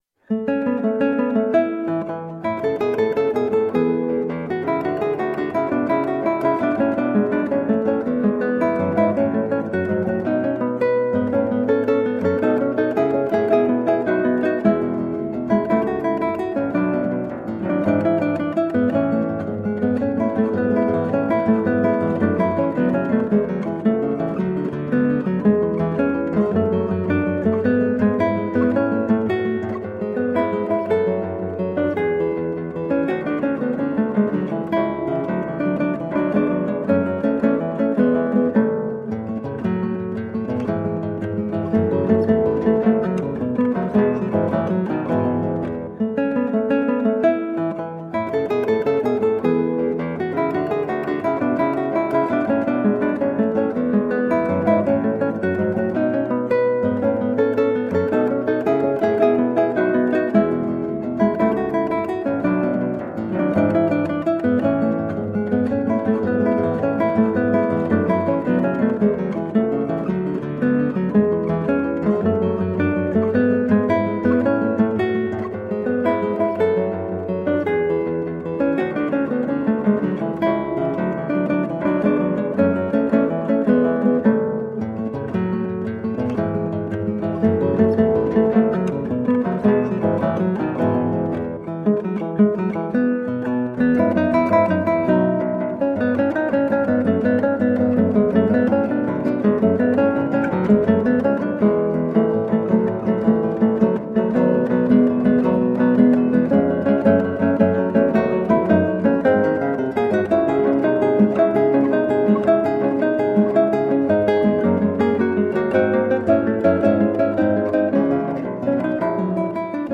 Colorful classical guitar.
Classical, Baroque, Instrumental